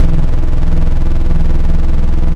ALIEN BASS 1.wav